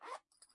拉链 " zipper1
描述：金属拉链从钱包或裤子被拉开
Tag: 金属 钱包 裤子 拉链 拉链